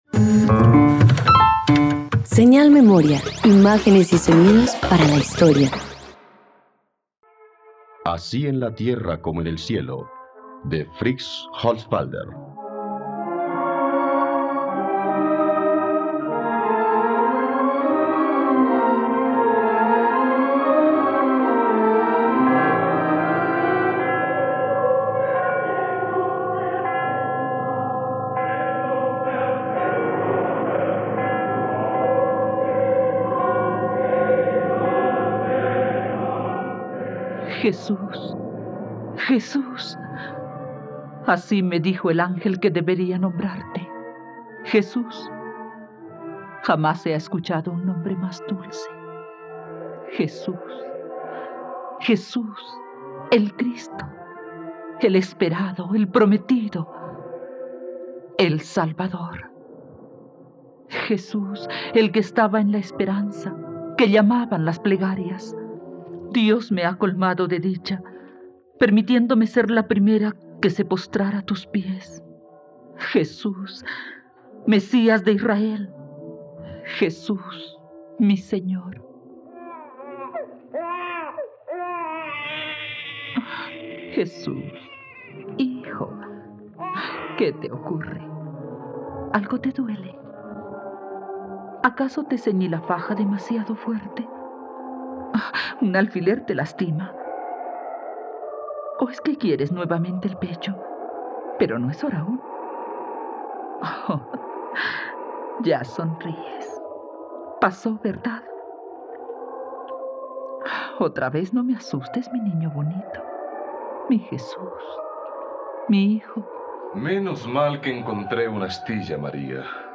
Así en la tierra como en el cielo - Radioteatro dominical | RTVCPlay